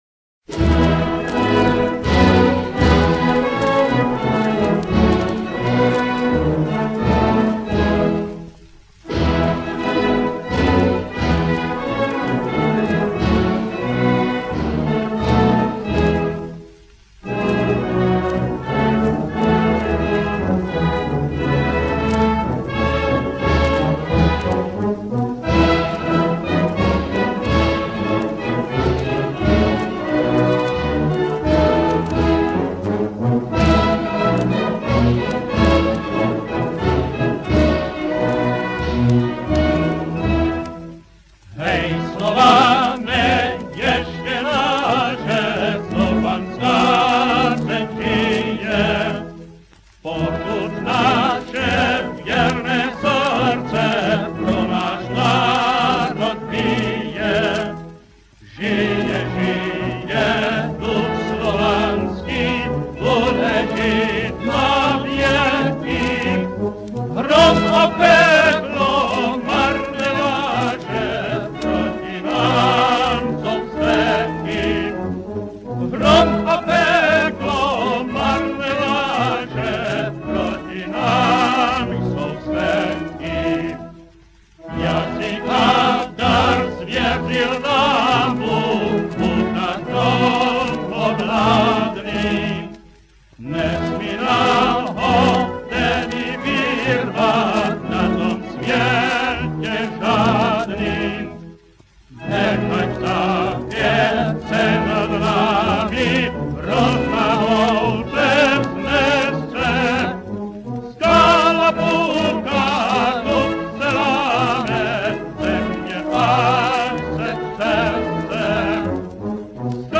Hymn[edit | edit source]
It is based upon a traditional Variyakoi folk poem written in the Variyakoi-Xhequan War. It consists of two verse and a chorus that is repeated twice; however often only the first verse and the chorus are sung.